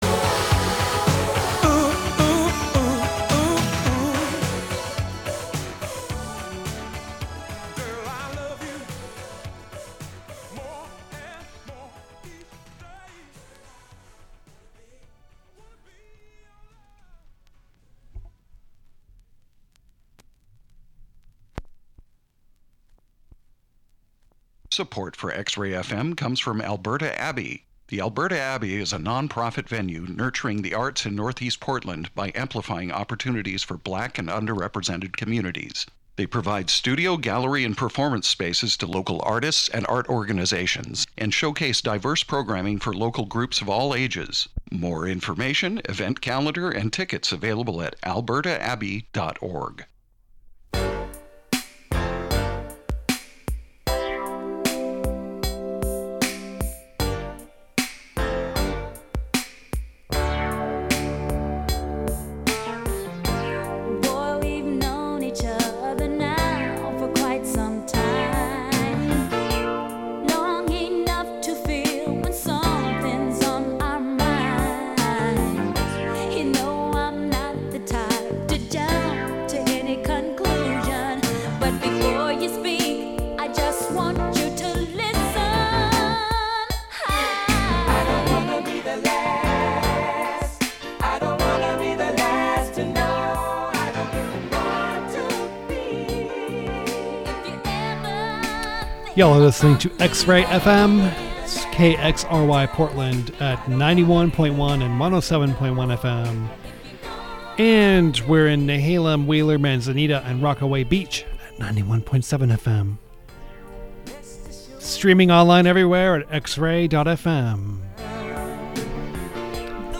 All original vinyl, from all over the world.